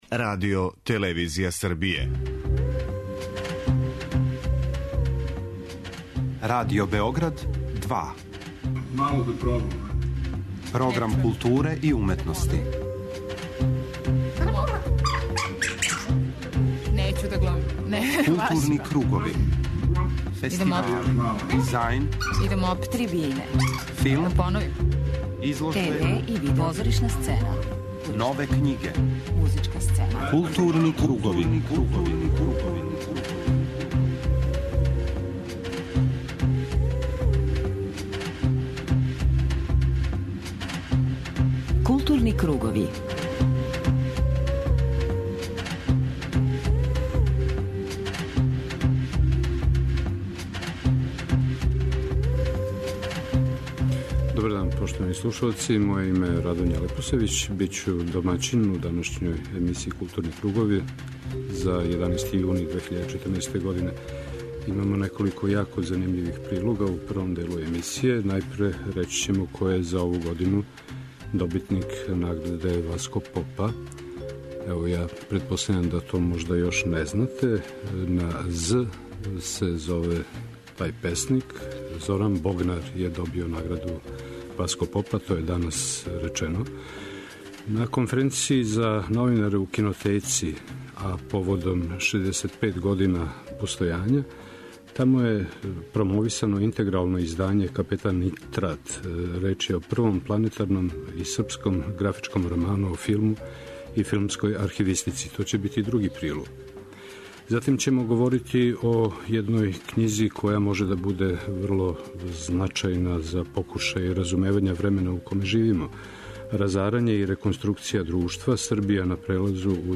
Осим интервјуа са Колхасом, чућемо и њене утиске о овој манифестацији.